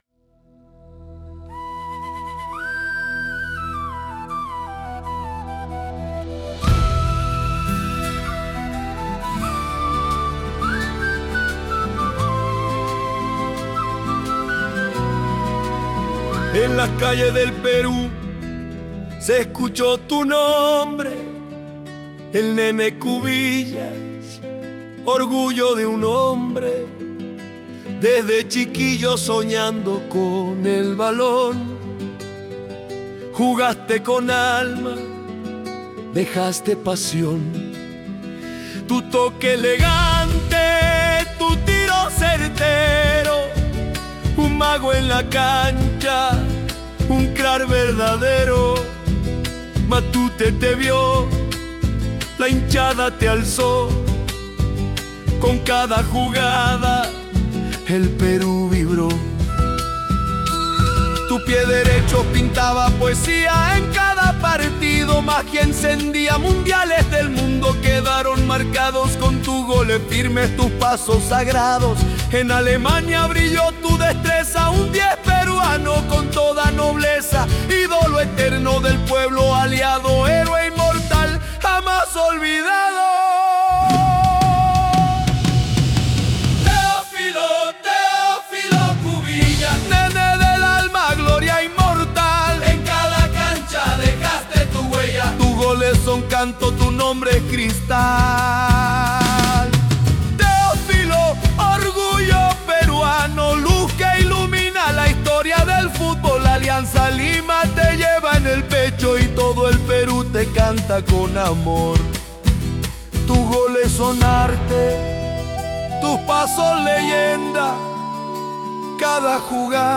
Himnos modernos inspirados en la historia, Matute y la pasión eterna del pueblo blanquiazul.
Himno moderno inspirado en la leyenda de Teófilo Cubillas y la garra histórica